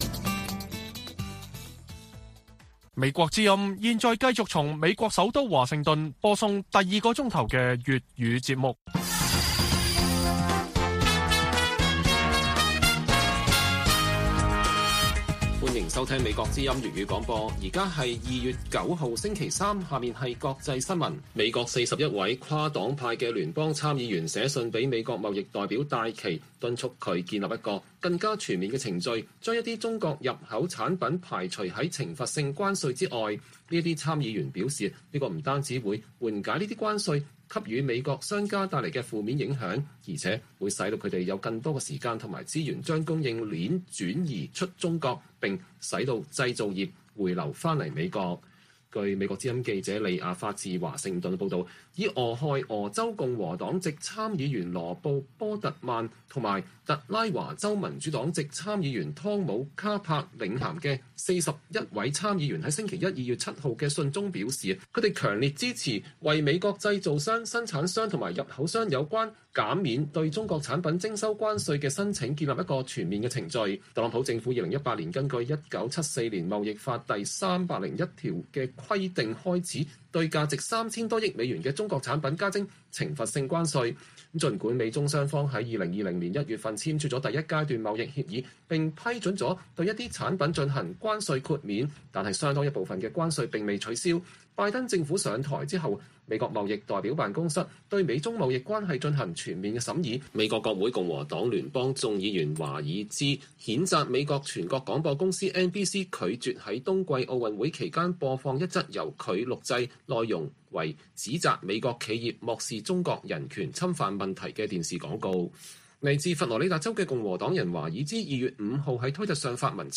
粵語新聞 晚上10-11點：41位美參議員敦促貿易代表建立更全面的對華關稅排除程序